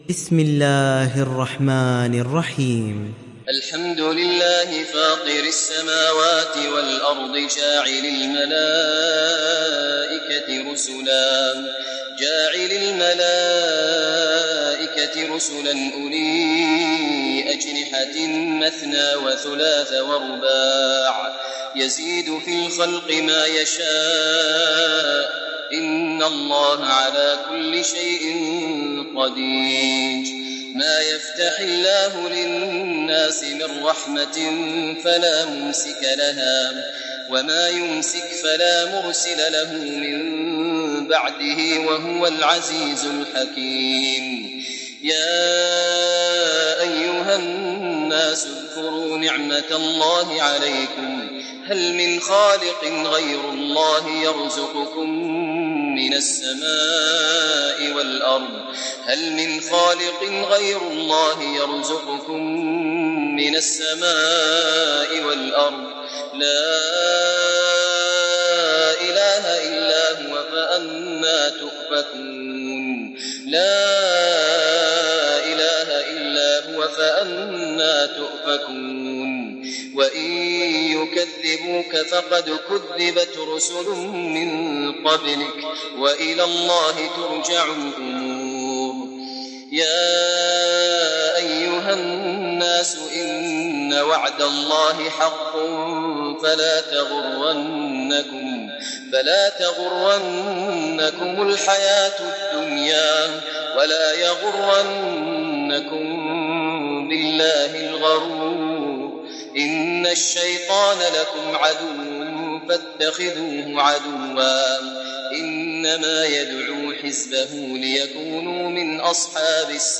Fatır Suresi mp3 İndir Maher Al Muaiqly (Riwayat Hafs)